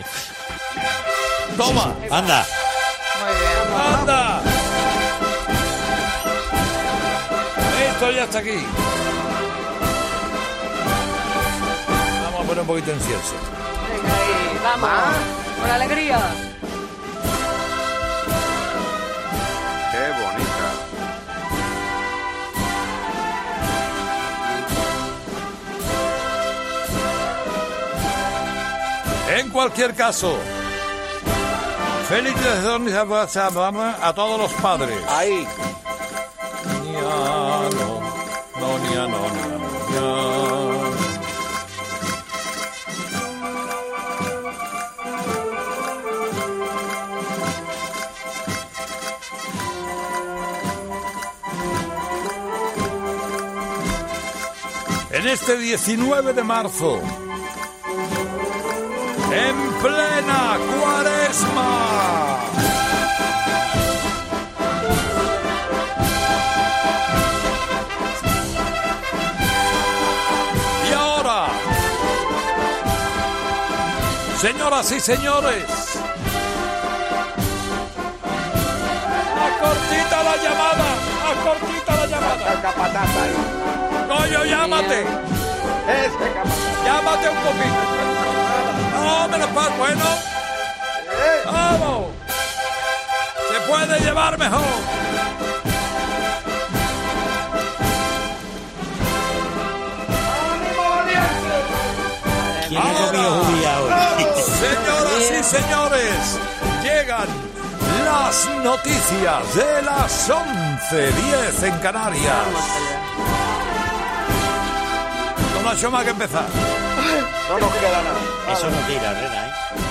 "¡Esto ya está aquí!", proclama Herrera al escuchar los primeros acordes.
"Es un género musical muy estimable. ¡Mira ésta qué preciosidad!", comentaba un apasionado Herrera mientras sonaba de fondo la 'Aurora de Santa Marina' de Abel Moreno Gómez.
"Todos los años cuando regresa la Vírgen de la Esperanza a la calle Pureza suena esta portentosa marcha que recoge la salve marinera de Triana", explicó mientras sonaba de fondo y se animaba a cantar.